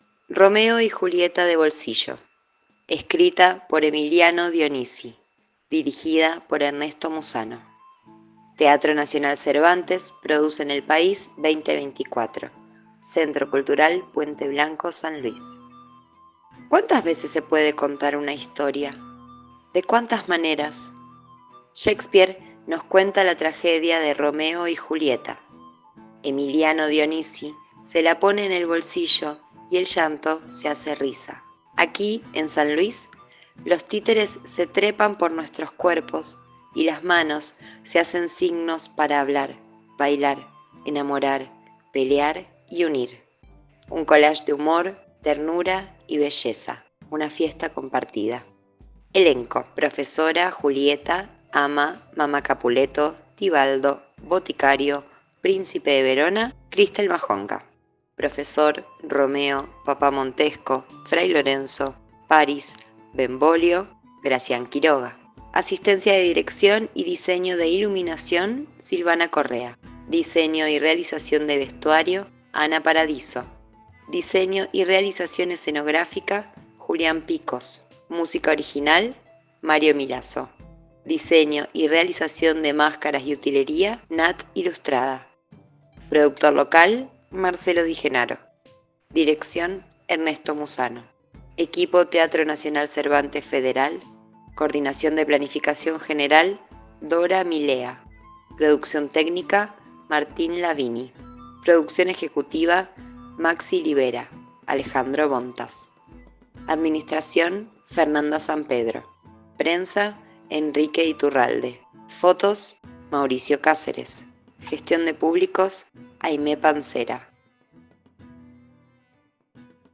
El siguiente audio corresponde a lectura del programa de mano del espectáculo Romeo y Julieta de bolsillo